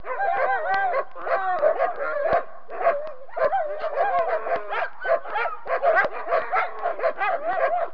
جلوه های صوتی
دانلود صدای حیوانات جنگلی 49 از ساعد نیوز با لینک مستقیم و کیفیت بالا